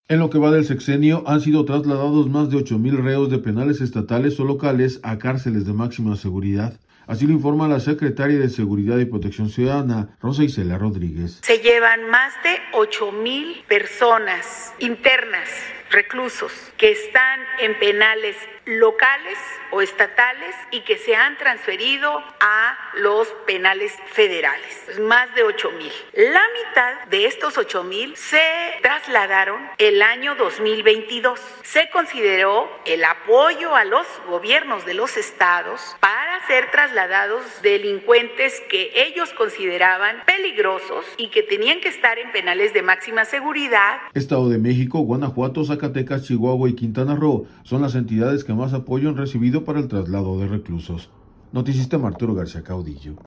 En lo que va del sexenio, han sido trasladados más de ocho mil reos de penales estatales o locales a cárceles de máxima seguridad, así lo informa la secretaria de Seguridad y Protección Ciudadana, Rosa Icela Rodríguez.